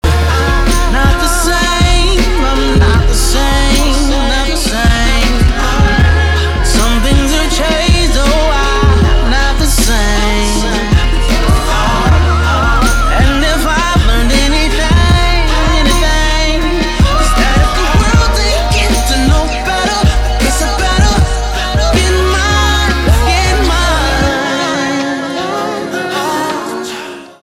рэп
хип-хоп , битовые , басы
чувственные